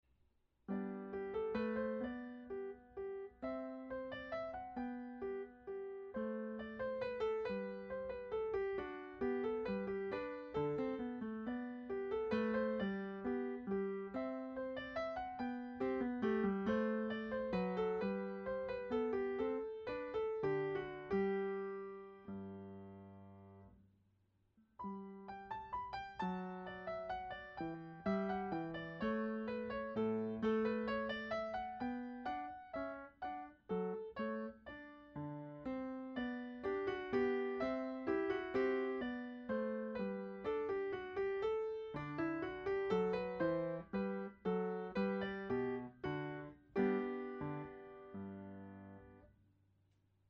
Johann_Sebastian_Bach_Minuet_Notebook_for_Anna_Magdalena.mp3